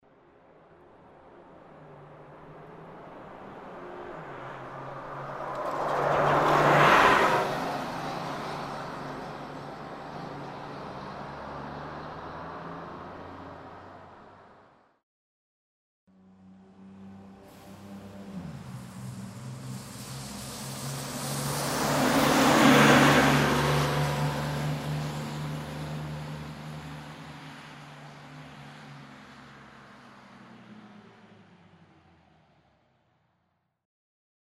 Автобус проезжает мимо остановки